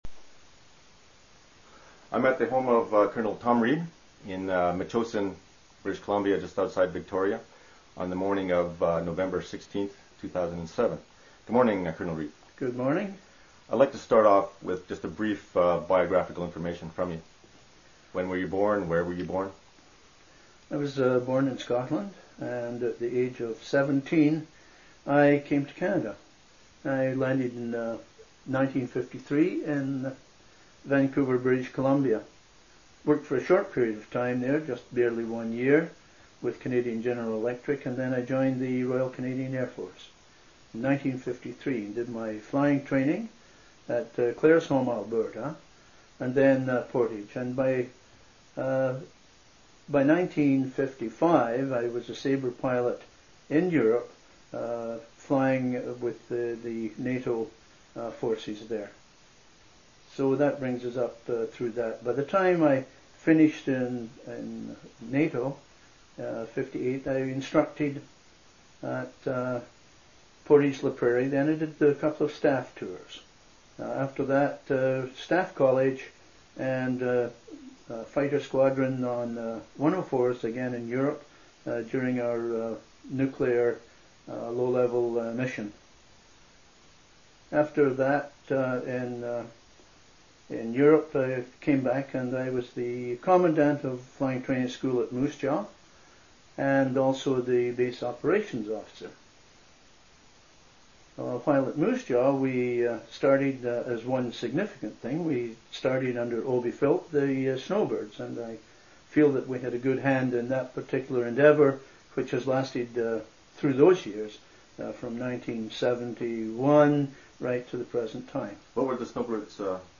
• Part of the Military Oral History Class collection of interviews.
• Canadian Military Oral History Collection